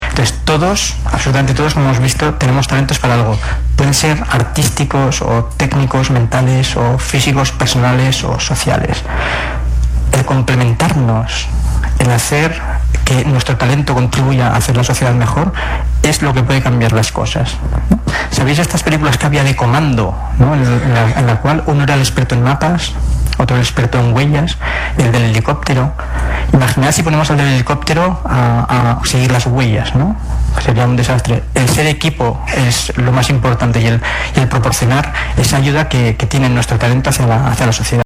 El prestigioso ponente desarrolló así una entretenida charla, rematada por un tiempo para el intercambio de opiniones y experiencias, presenciales y a través de Twitter a partir de su tesis fundamentada en que “todos tenemos diversos talentos, lo que no significa salir en un concurso de televisión. Es algo que te hace tender hacia tu felicidad y que se debe usar para colaborar y contribuir a construir una sociedad mejor”.